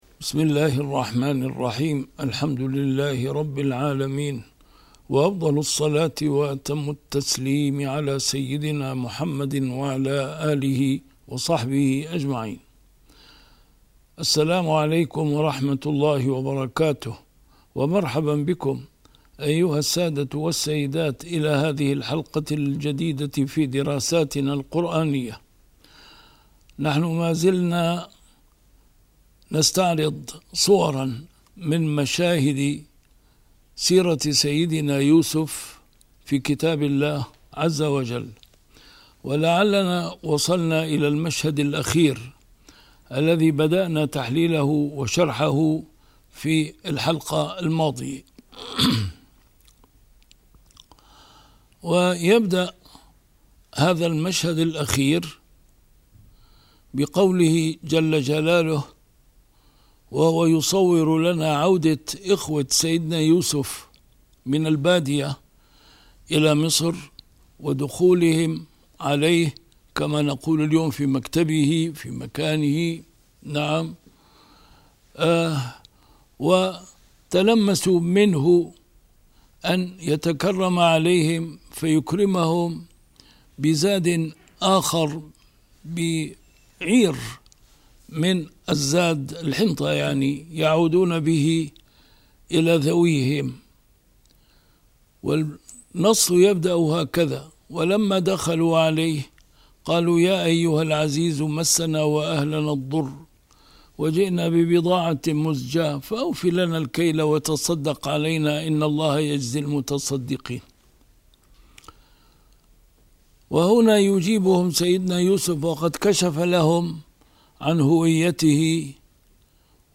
A MARTYR SCHOLAR: IMAM MUHAMMAD SAEED RAMADAN AL-BOUTI - الدروس العلمية - مشاهد من قصة سيدنا يوسف في القرآن الكريم - 11 - تأويل رؤيا سيدنا يوسف